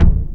GONE, GONE Kick.wav